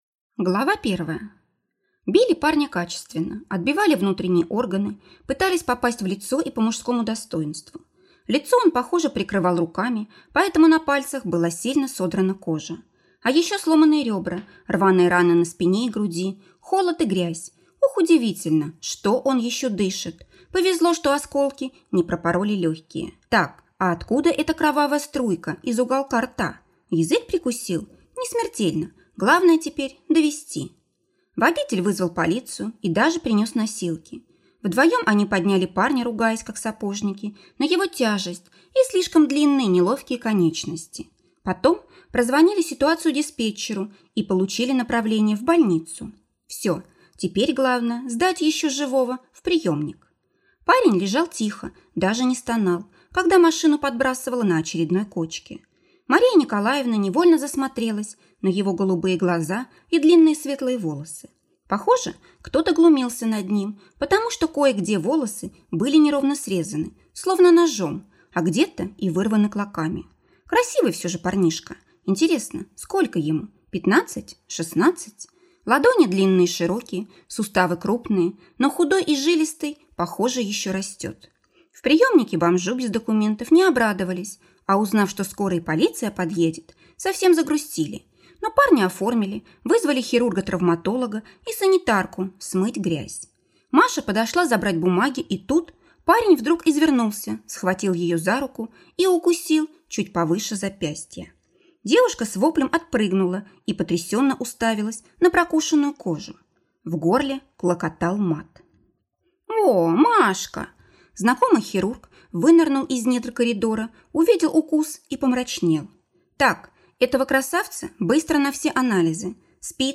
Аудиокнига Случайное совпадение | Библиотека аудиокниг